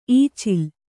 ♪ īcil